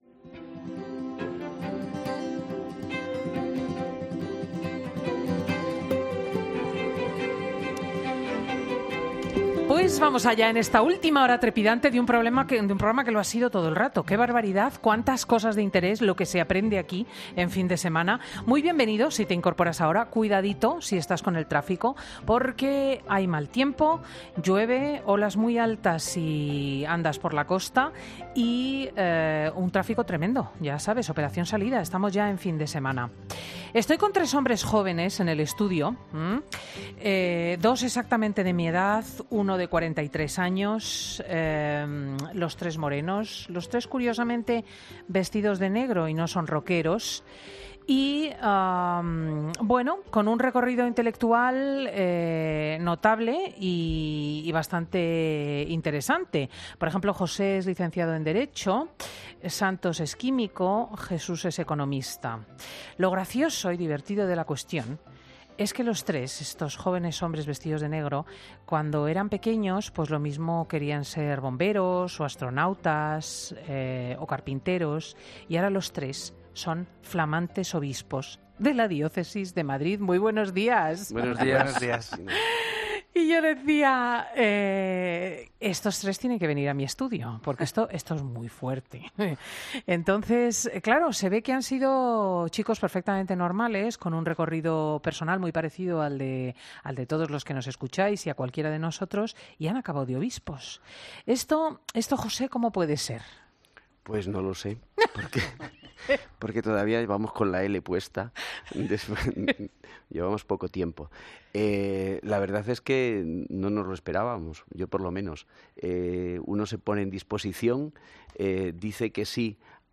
Monseñor José Cobo, Monseñor Santos Montoya y Monseñor Jesús Vidal son tres hombres jóvenes que cuentan cómo decidieron escoger el camino de Dios
ESCUCHA AQUÍ LA ENTREVISTA COMPLETA Monseñor Jesús Vidal, es el más joven de los tres.